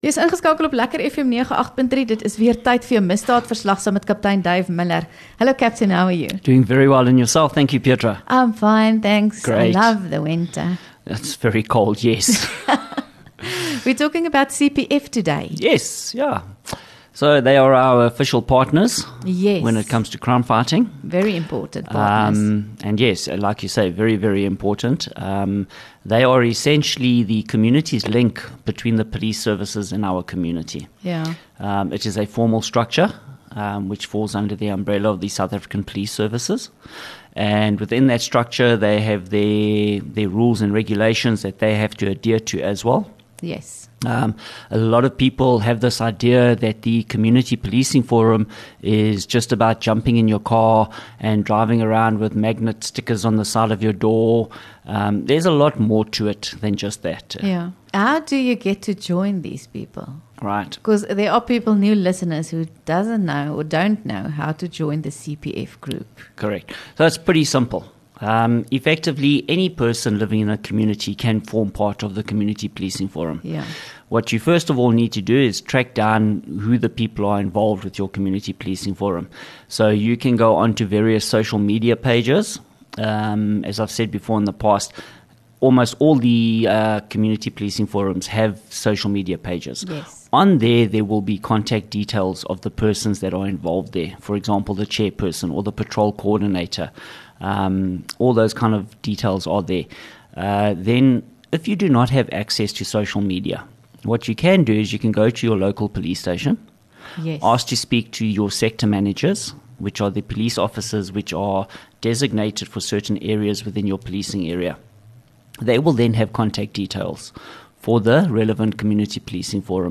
LEKKER FM | Onderhoude 18 Jun Misdaadverslag